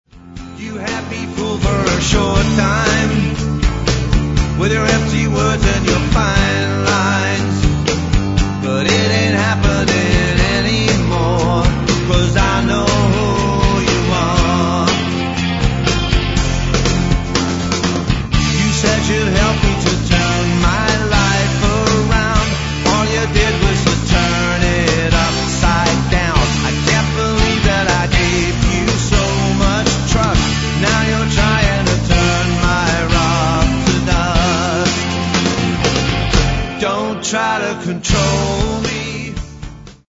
lead vocals, guitar, keyboards, dobro
electric and acoustic guitars
bass guitar
drums
percussion
7-string guitar
acoustic guitar, backing vocals
backing vocals